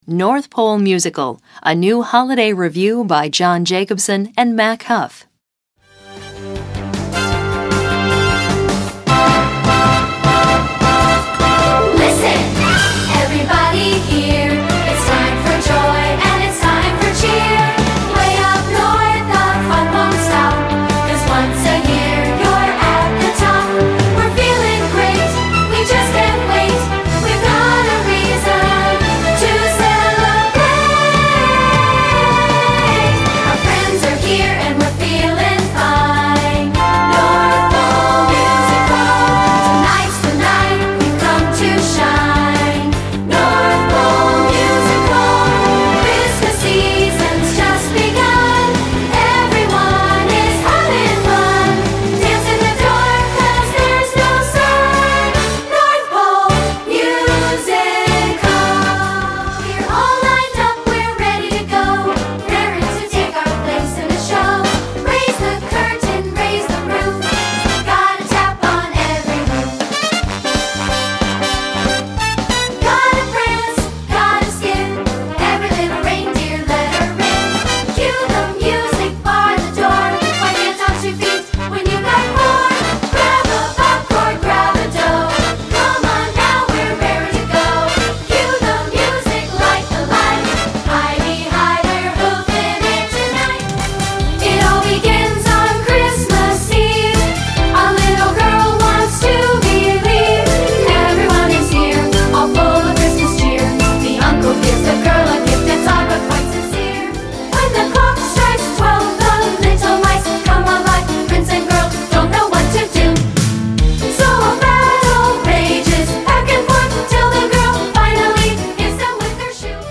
Holiday Revue